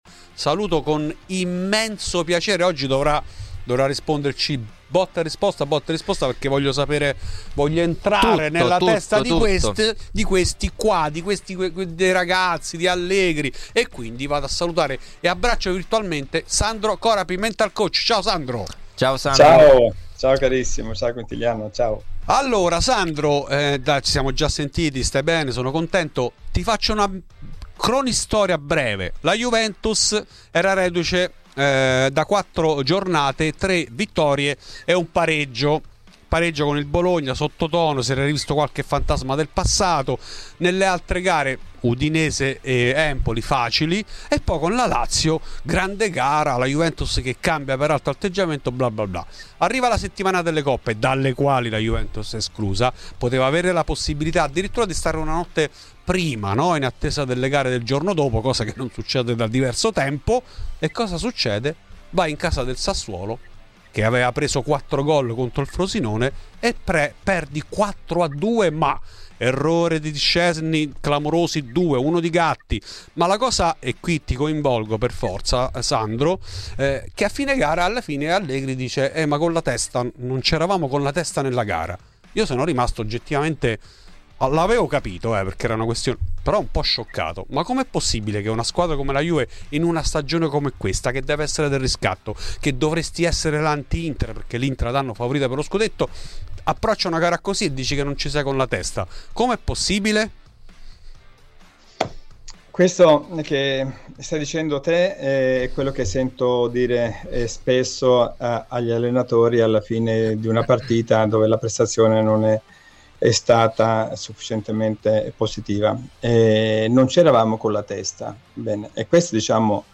Resta da capire la metamorfosi negativa rispetto alla splendida vittoria ottenuta contro la Lazio. Intanto torna d'attualità il problema legato alla mancanza di un leader nello spogliatoio. In ESCLUSIVA a Fuori di Juve il Mental coach